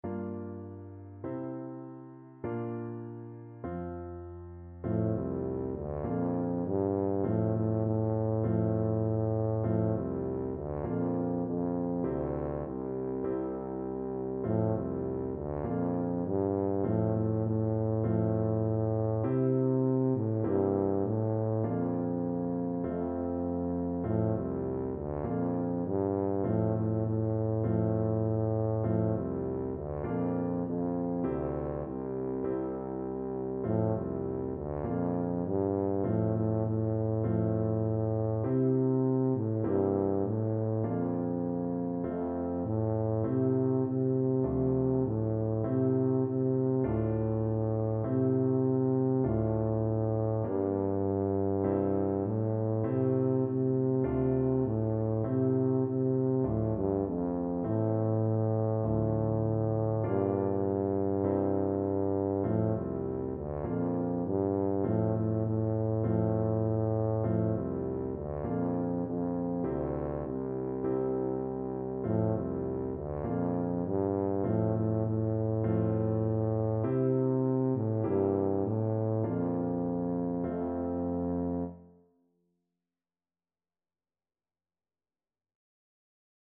4/4 (View more 4/4 Music)
F major (Sounding Pitch) (View more F major Music for Tuba )
Tuba  (View more Easy Tuba Music)